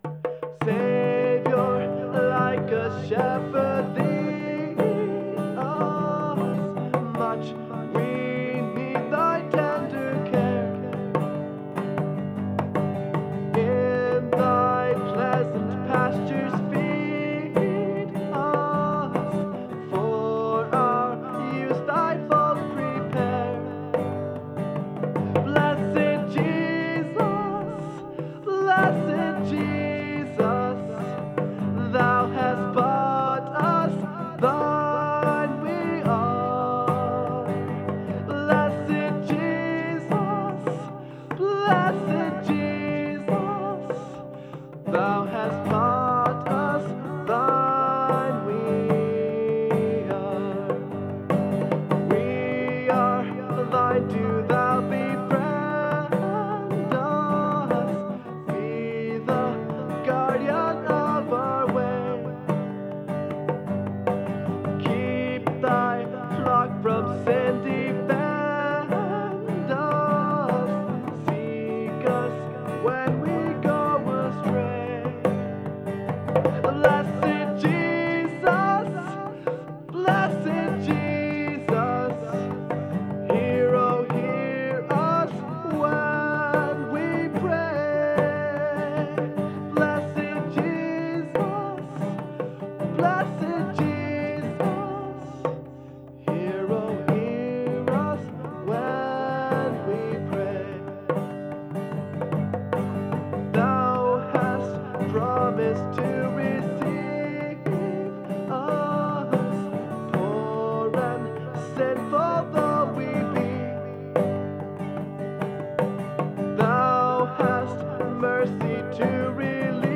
Genre(s): Acoustic
Instrumentation: Djembe, Guitar, Vocals